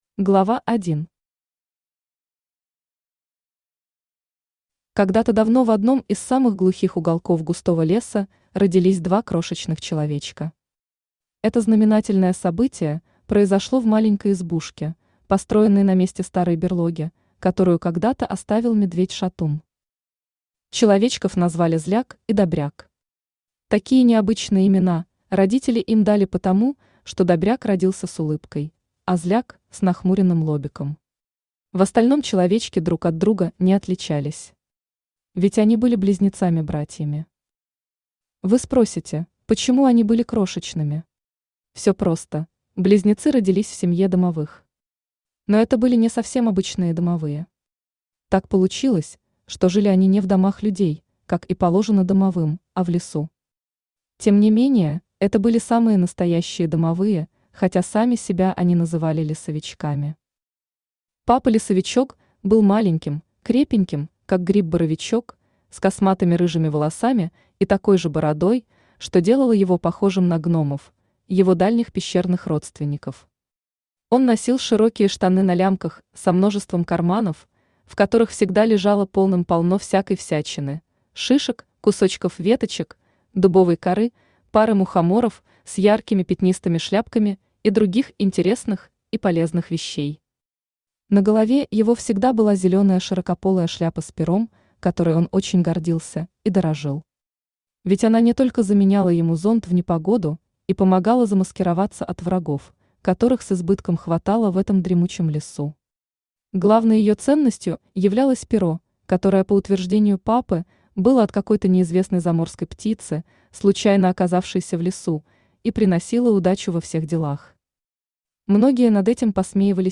Аудиокнига Добряк и Зляк | Библиотека аудиокниг
Aудиокнига Добряк и Зляк Автор Аркадий Неминов Читает аудиокнигу Авточтец ЛитРес.